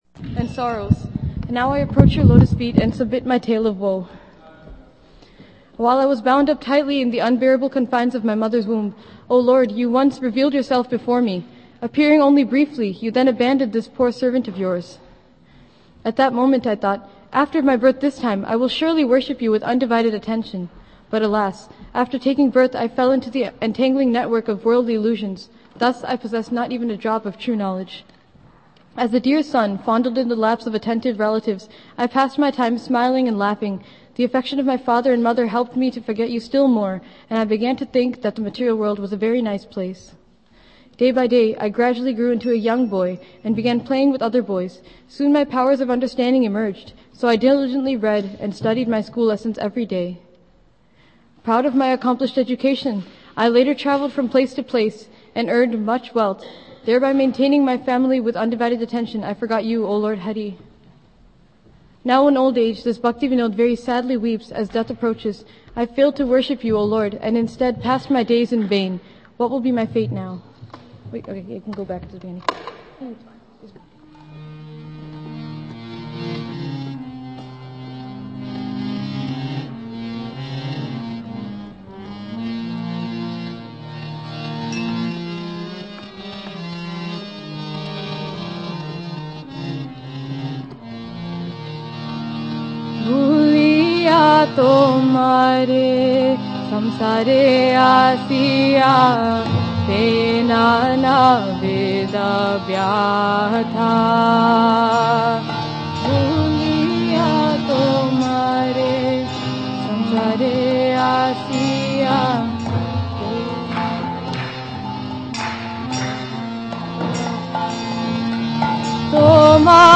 Bhajans on the theme of the Retreat by SoulConnection